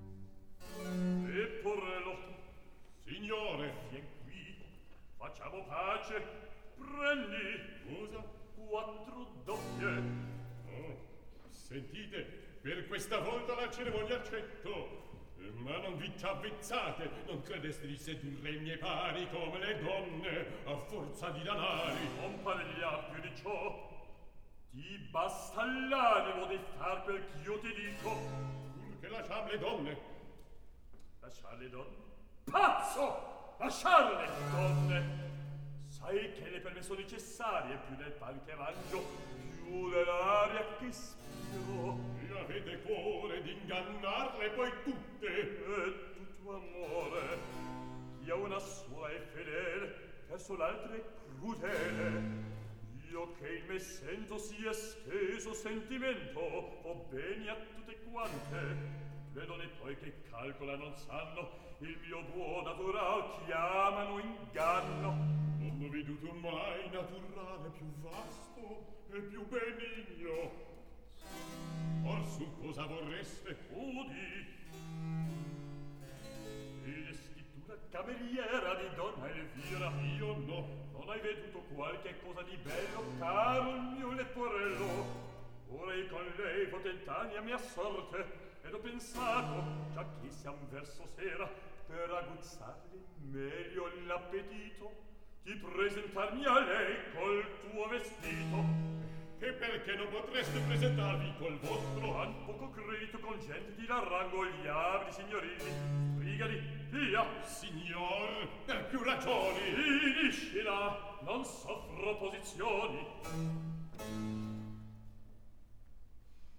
Recitativo.